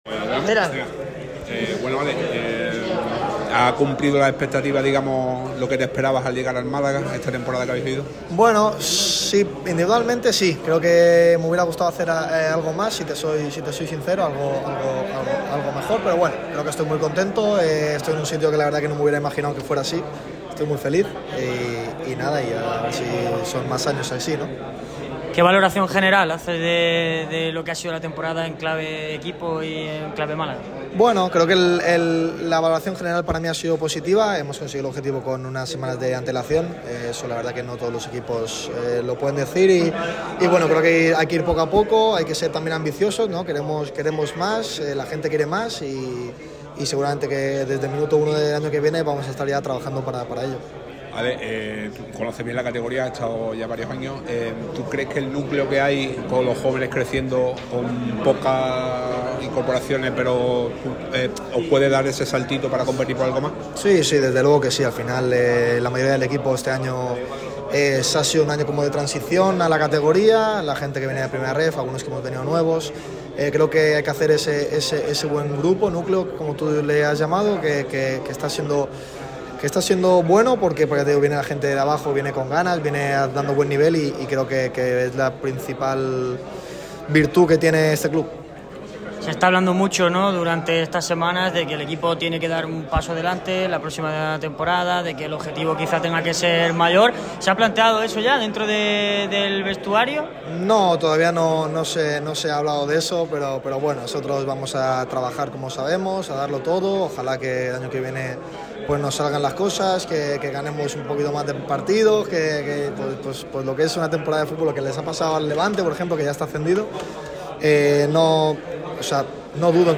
Este jueves 28 de mayo se ha celebrado en las instalaciones de La Rosaleda el ‘Media Day’ del Málaga CF con motivo del final de temporada que tendrá lugar el próximo sábado a las 18:30 en casa ante el Burgos. El defensa Álex Pastor fue uno de los que compareció ante los medios. El barcelonés ha repasado muchos temas de interés, entre los que destacan la valoración de la temporada, su papel desde su llegada en verano y los objetivos para el siguiente curso.